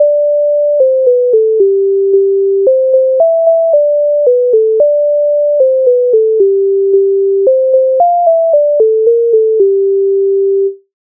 MIDI файл завантажено в тональності G-dur
Ой коли б той вечір Українська народна пісня з обробок Леонтовича с. 141